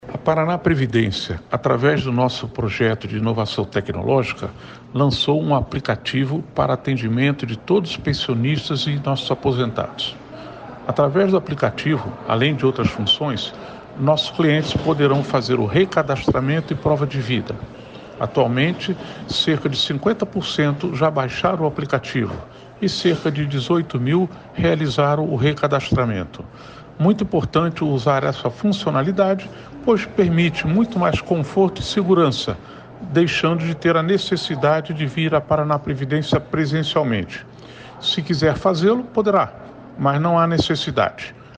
Sonora do diretor-presidente da ParanaPrevidência, Felipe Vidigal, sobre o recadastramento de beneficiários de 2023